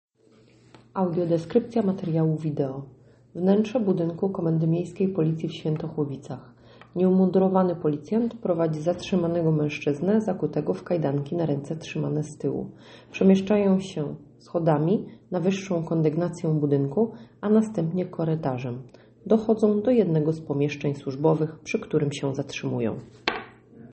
Nagranie audio audiodeskrypcja materiału wideo